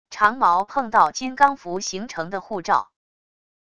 长矛碰到金刚符形成的护罩wav音频